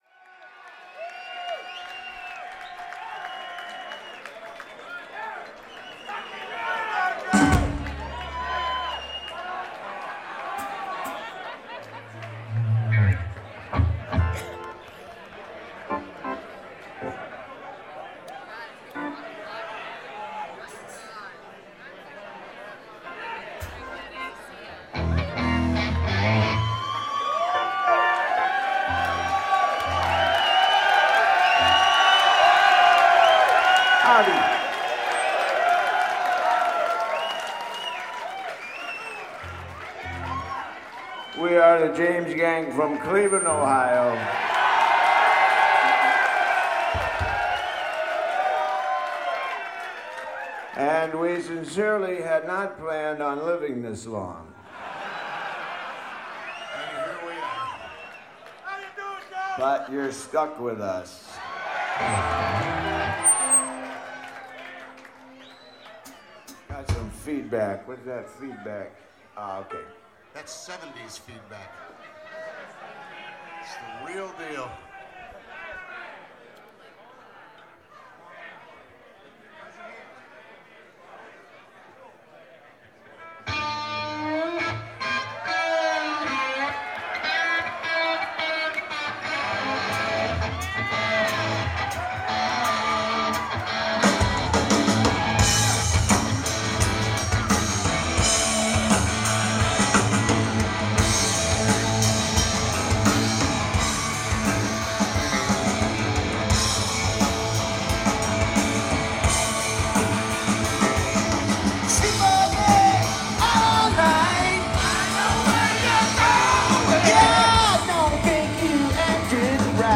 Live Audio Treats